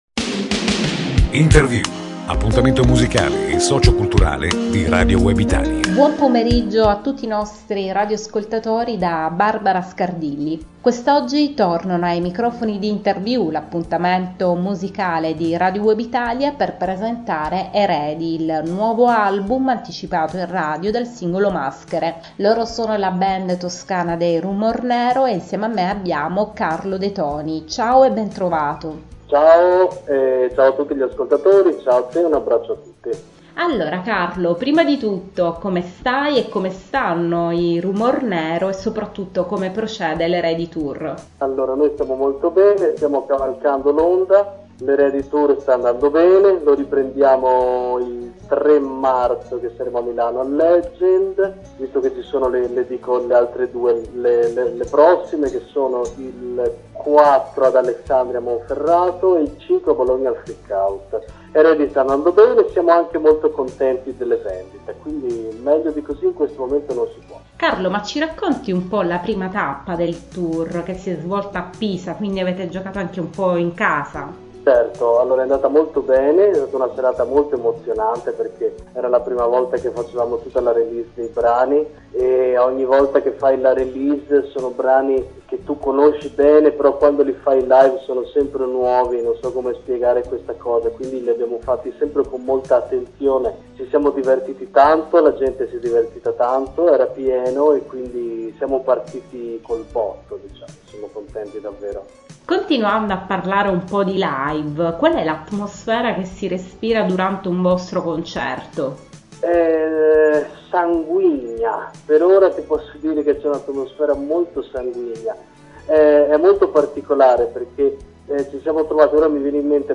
I RHumornero ospiti di Radio Web Italia presentano Eredi, il nuovo album - Radio Web Italia